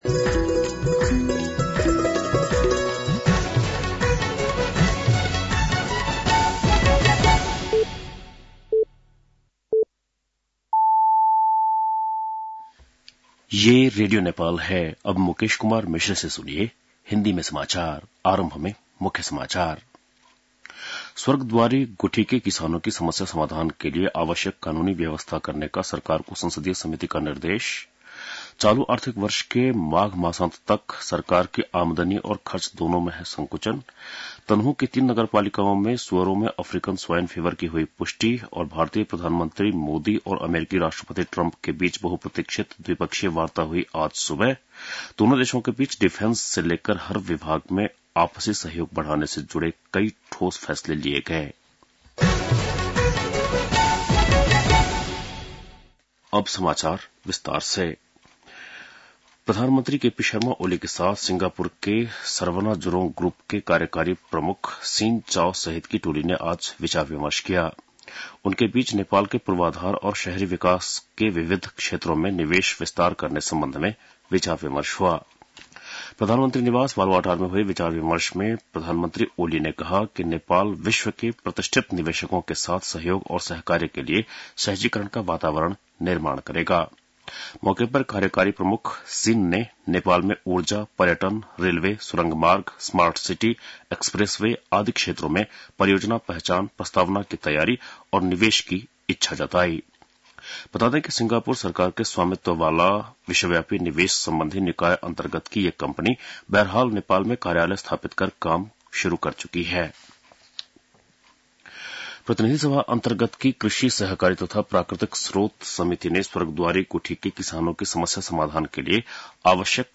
बेलुकी १० बजेको हिन्दी समाचार : ३ फागुन , २०८१